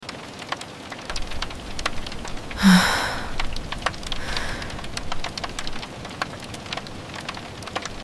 ASMR To Fall Asleep Instantly sound effects free download